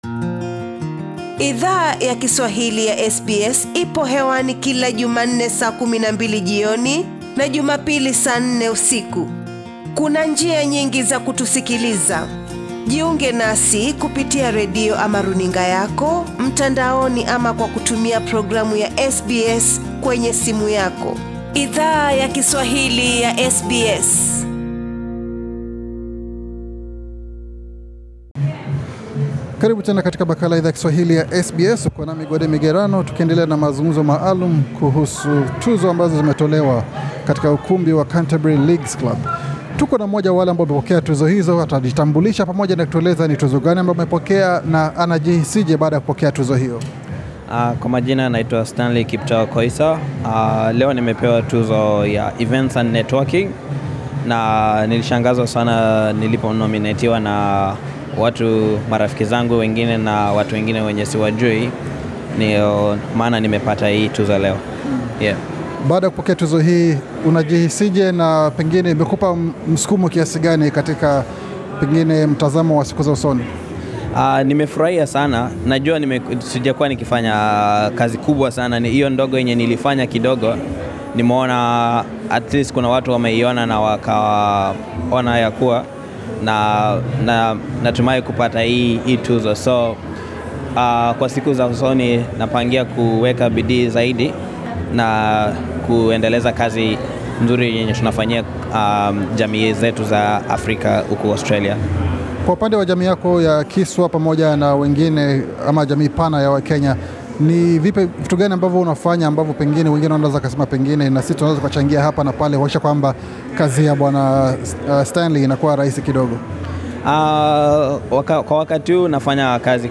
Wanachama wa jamii yawa Kenya, walikuwa miongoni mwa washindi, katika sherehe iliyo andaliwa na kitengo cha NSW cha shirika la Celebration of African Australian. Baadhi ya washindi wa tuzo hizo, wali changia hisia zao na Idhaa ya Kiswahili ya SBS.